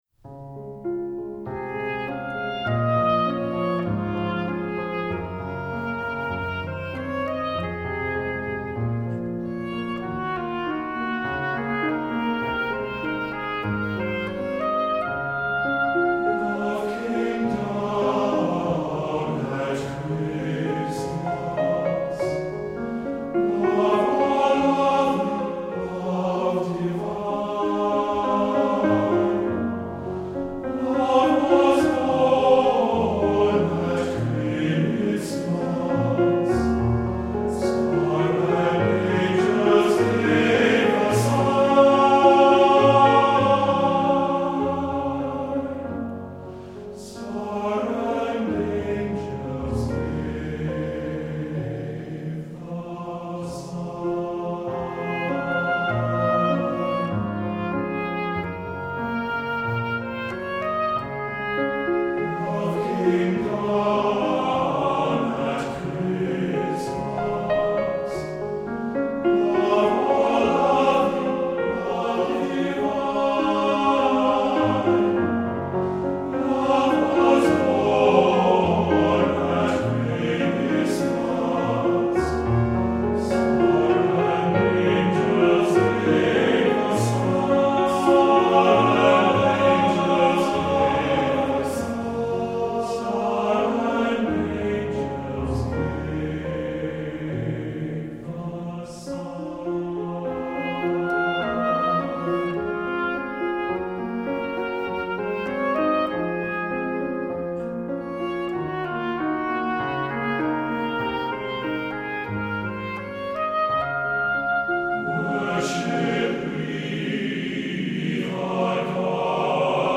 Voicing: TTBB and Piano